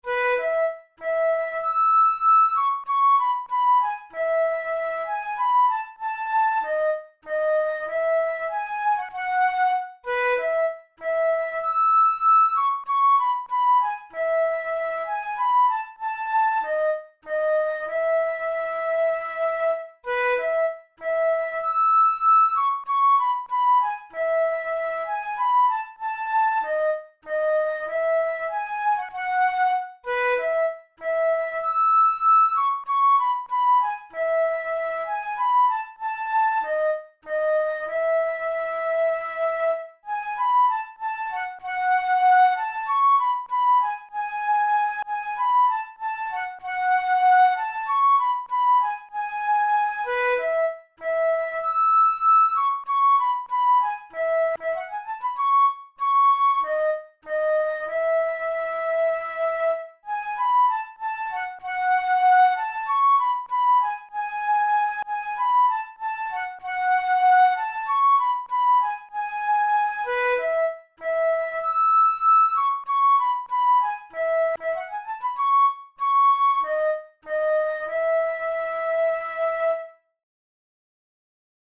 InstrumentationFlute solo
KeyE major
RangeB4–E6
Time signature2/4
Tempo96 BPM